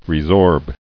[re·sorb]